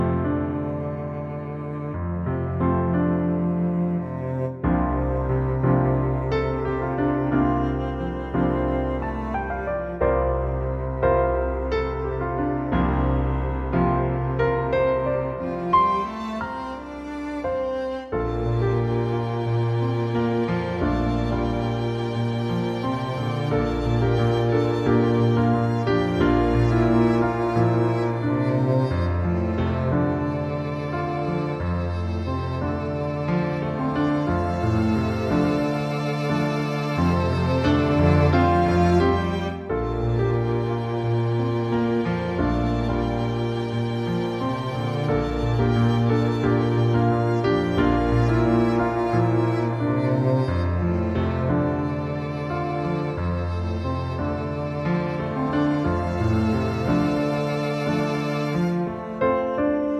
Down 4 Semitones